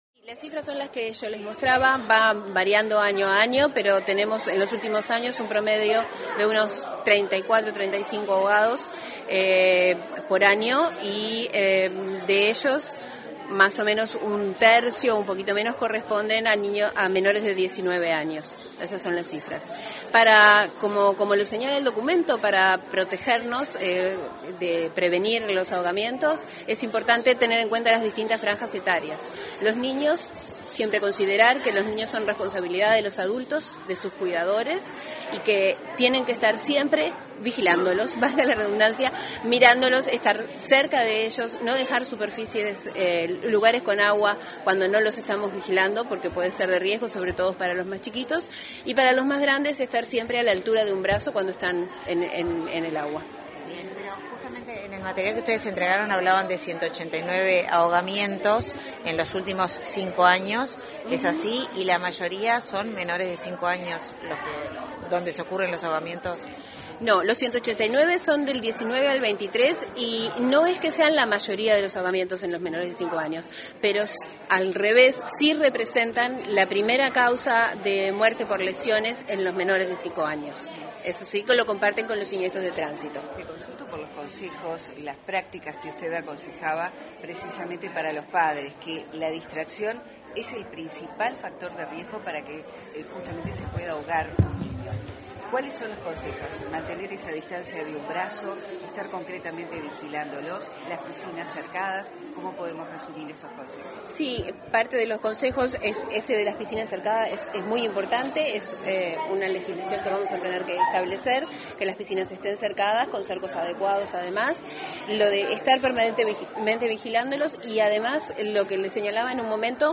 Declaraciones de la integrante del Programa de Salud de la Niñez del MSP